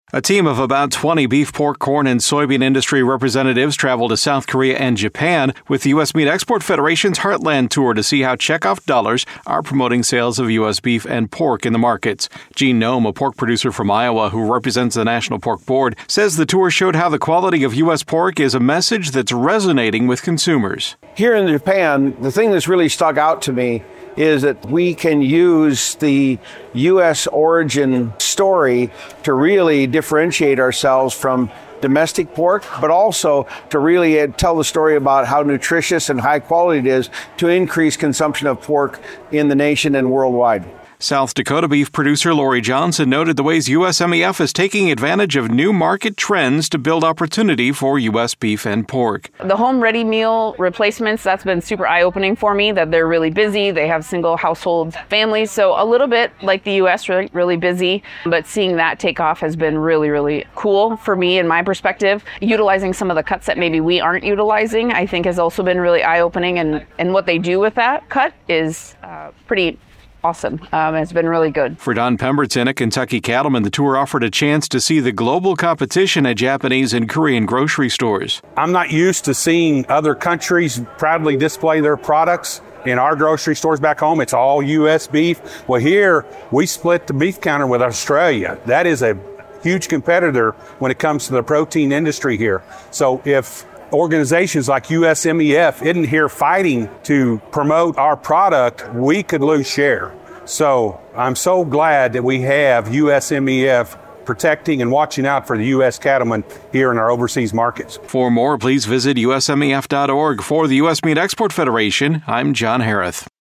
In this USMEF Audio Report, three of the tour participants share observations about the Korea and Japan markets and how U.S. red meat is being marketed there.